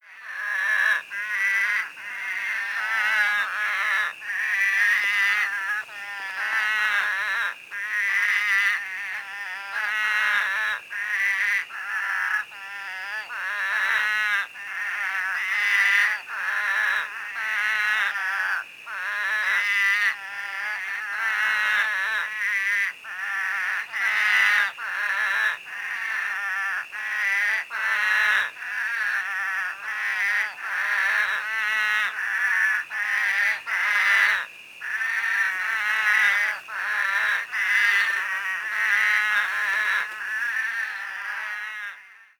Spadefoot Chorus
Spadefoot Toads in Sabino Canyon
1508-Spadefoot-Chorus.mp3